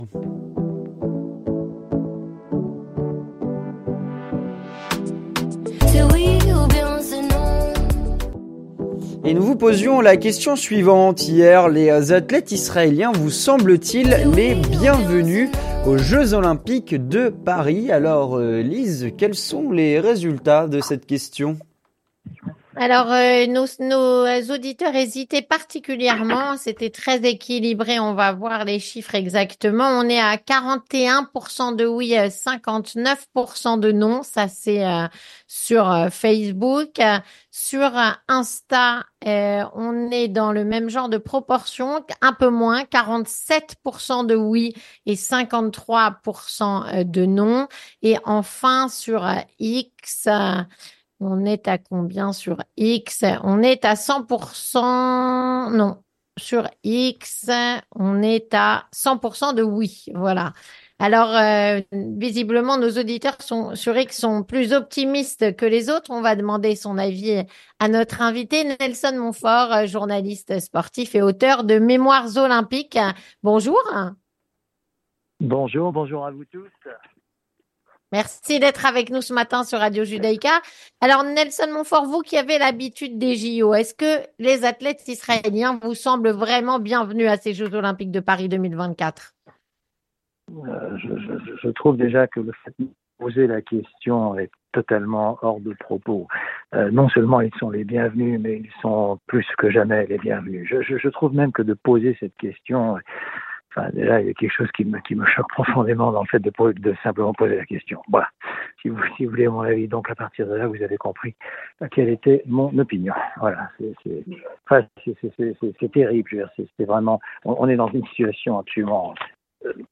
Avec Nelson Montfort, journaliste de sport et auteur de “Mémoires olympiques”